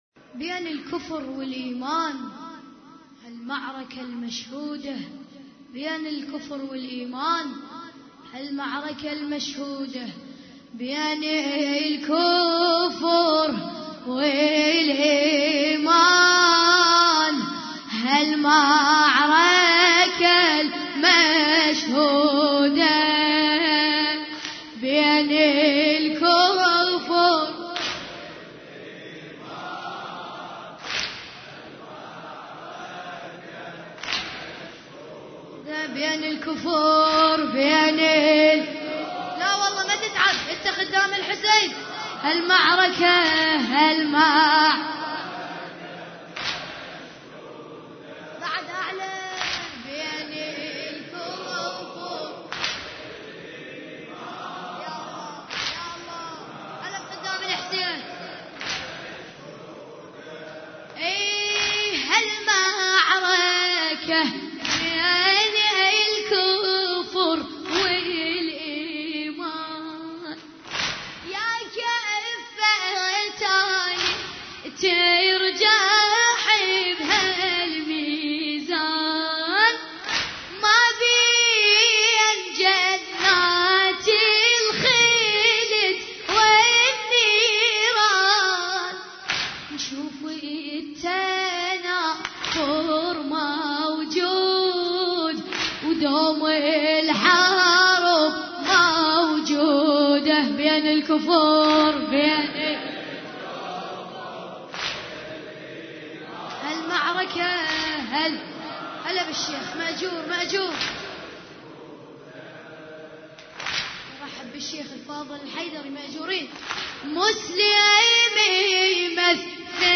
عزاء ليلة 5 محرم 1433 هجري